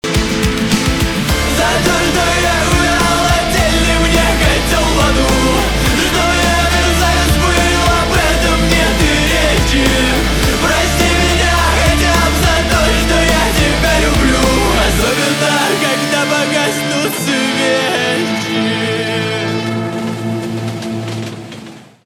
русский рок
грустные , гитара , барабаны